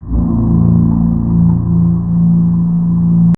1 channel
Bass01C.wav